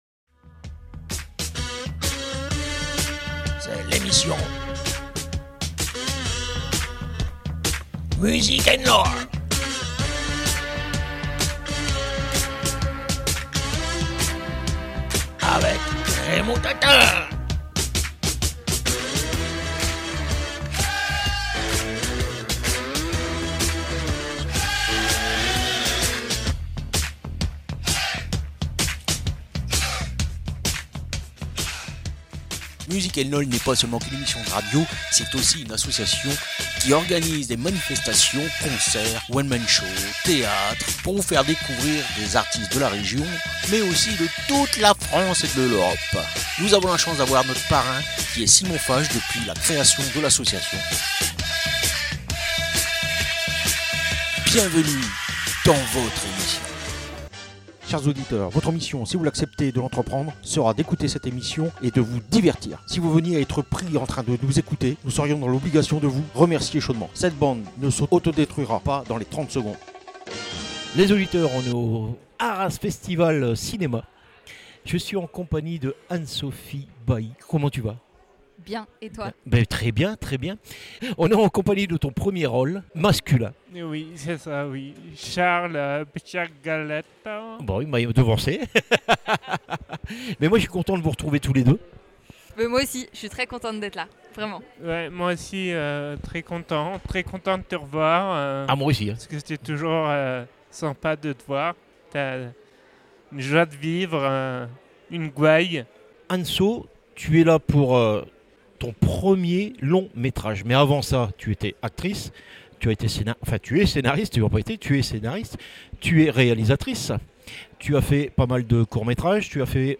Music & lol – Rencontre avec des comédiens du film Mon inséparable